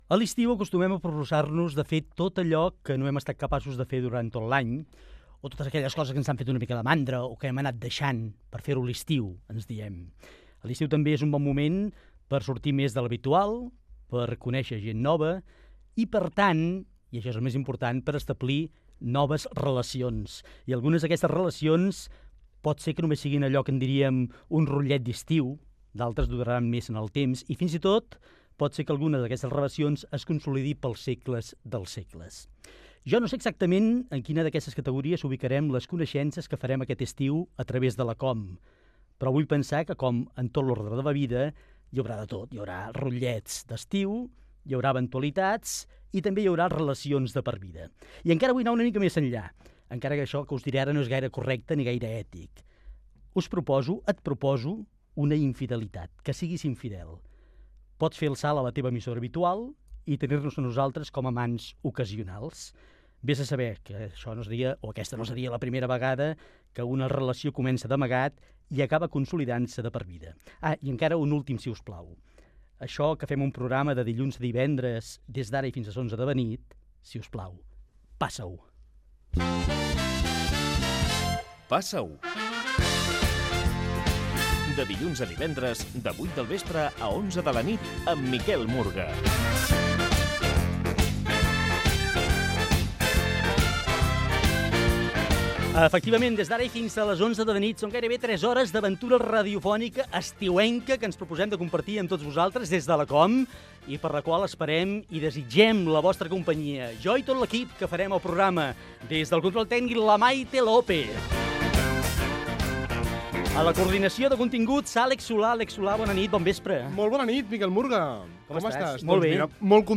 Magazín d'actualitat lúdica i cultural.